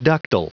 Prononciation du mot ductal en anglais (fichier audio)
Prononciation du mot : ductal